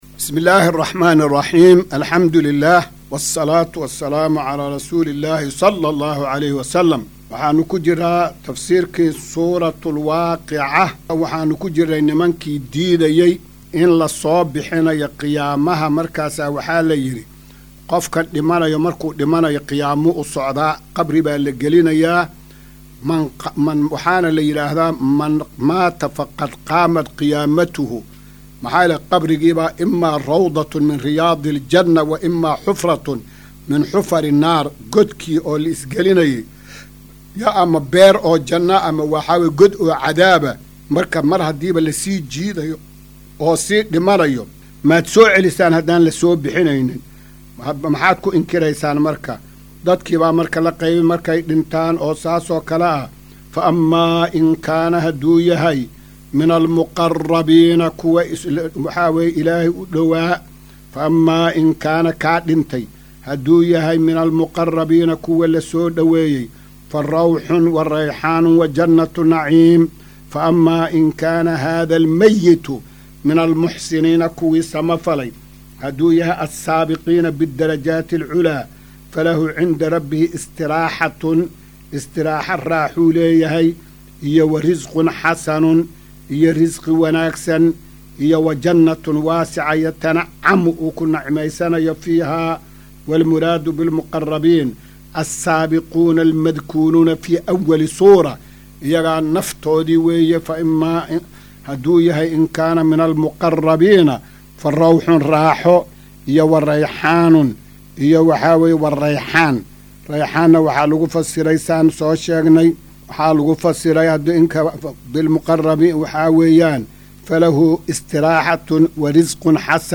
Maqal:- Casharka Tafsiirka Qur’aanka Idaacadda Himilo “Darsiga 256aad”